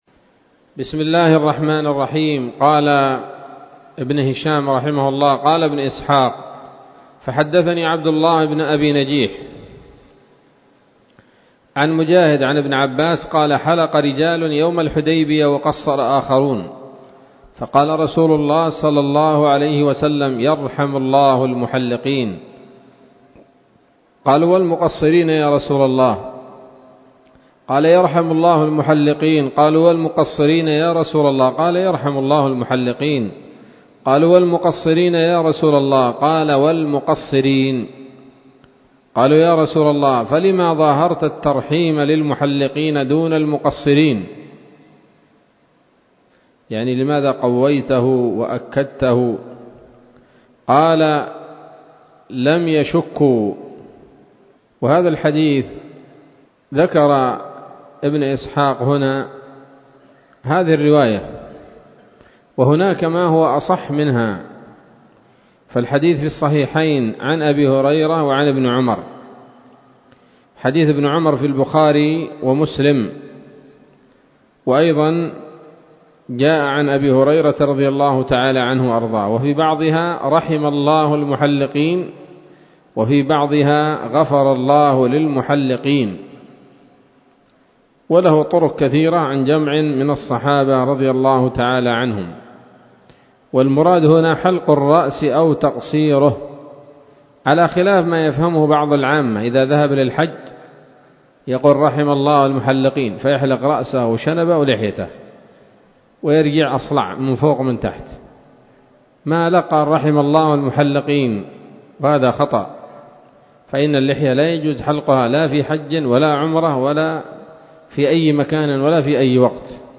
الدرس الرابع والثلاثون بعد المائتين من التعليق على كتاب السيرة النبوية لابن هشام